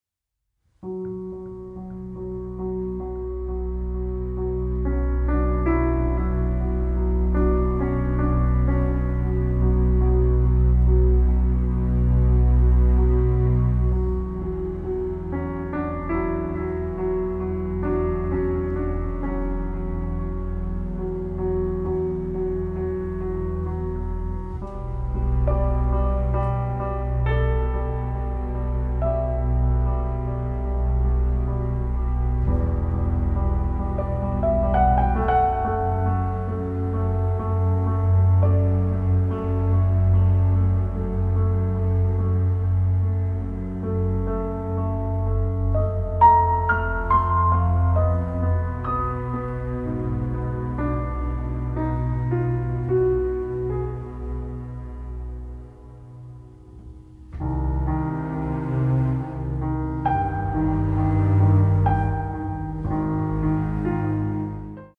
Recorded at Air Studios Lyndhurst Hall in London